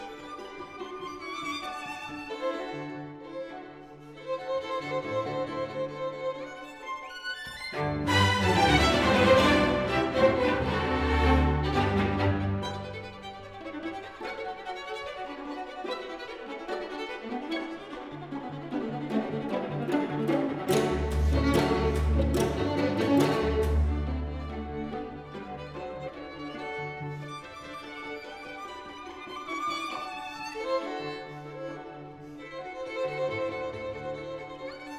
Струнные и рояль
Жанр: Классика